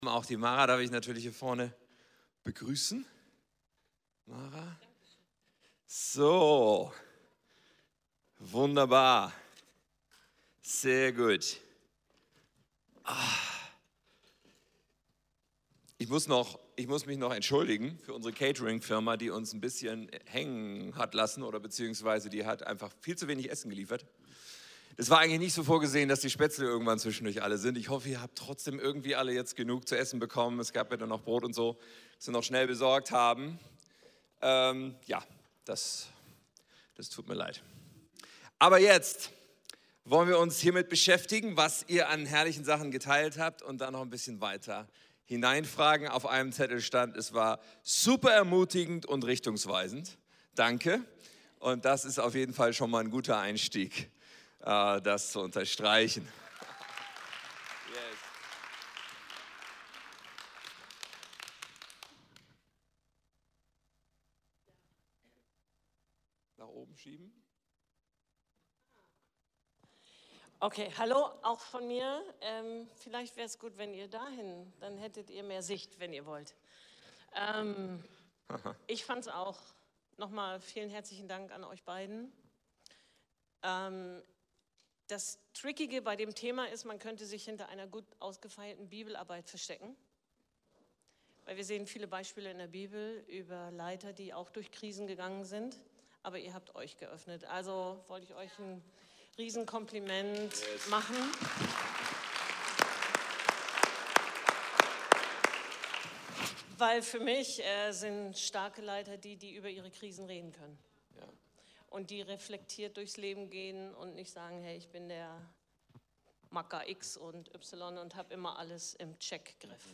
Vor einigen Wochen war ich auf einer Regionalkonferenz zu einem Vortrag eingeladen.
Ergänzt durch die Q & A Zeit, in der die Konferenzteilnehmer konkret nachfragen konnten.